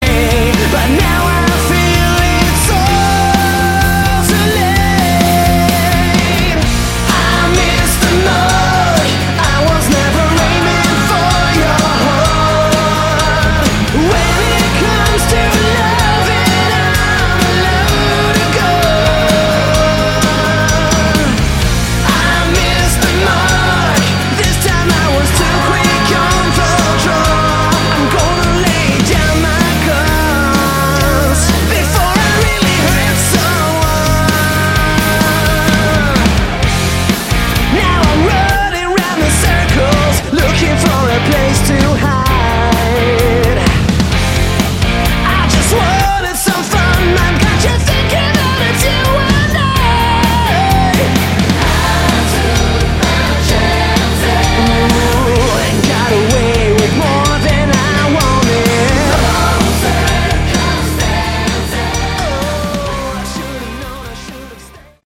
Category: Hard Rock
guitar, backing vocals